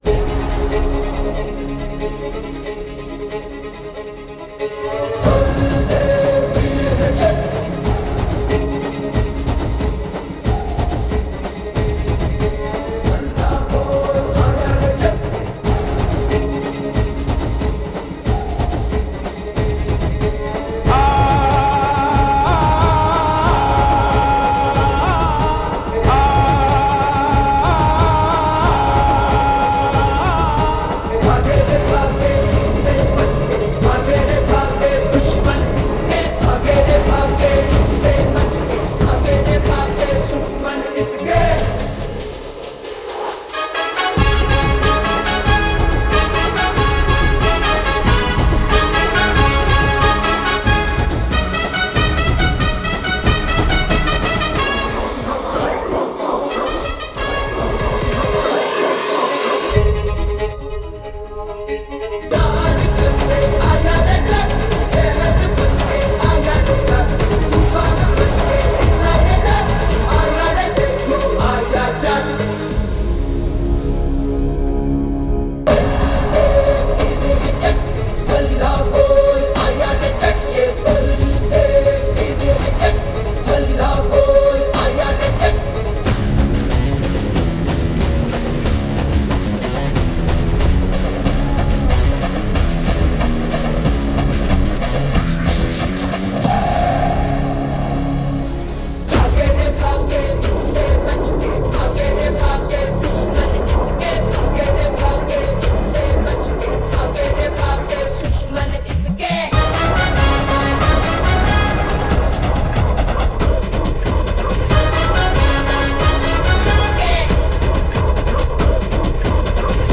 without fight sounds and disturbances